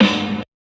Snares
SNARE 90S 4.wav